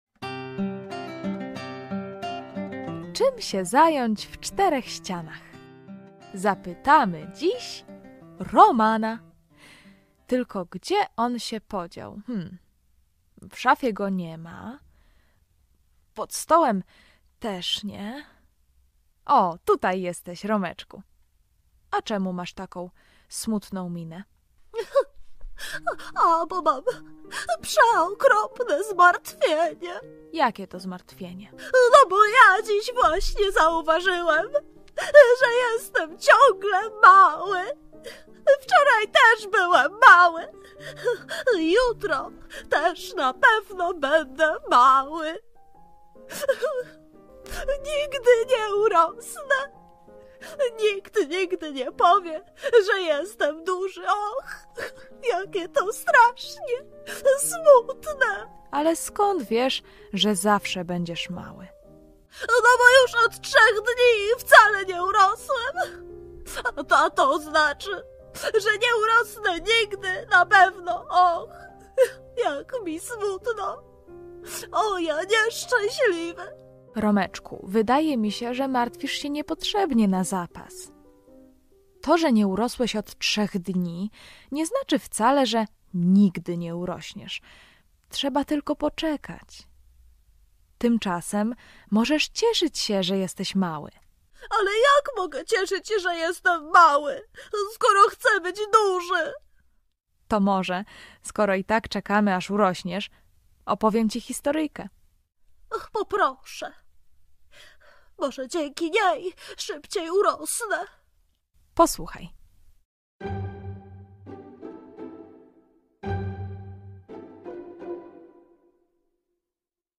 Wieczorynka IPP: Romek się MARTWI. Czy NIGDY nie UROŚNIE? [ANIMOWANA AUDYCJA DLA DZIECI]